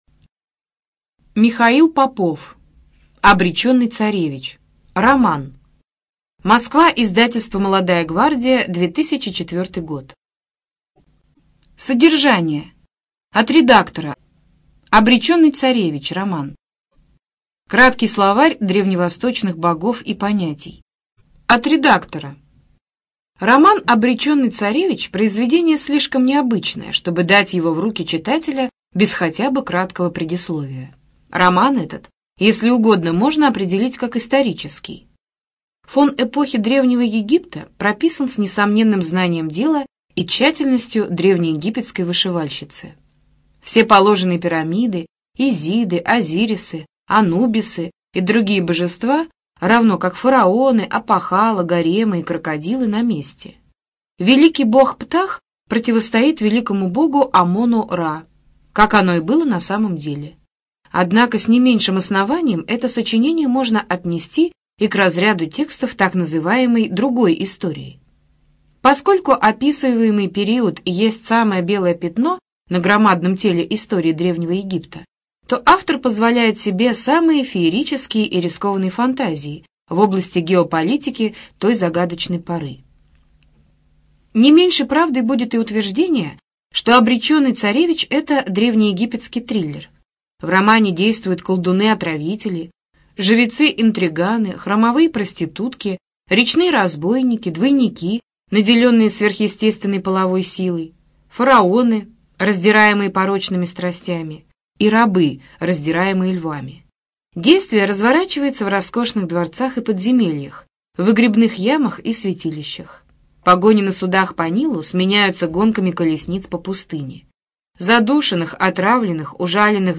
ЖанрИсторическая проза
Студия звукозаписиКемеровская областная специальная библиотека для незрячих и слабовидящих